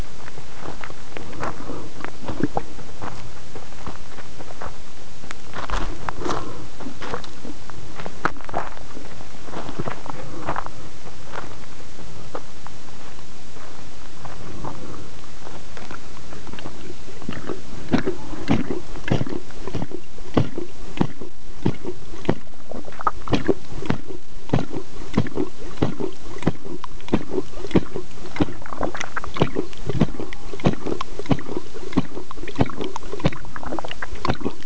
Through a collaborative effort between computer scientists, engineers, and zoologists, custom designed acoustic bio-loggers were fitted to eight lions and recorded audio simultaneously with accelerometer and magnetometer data.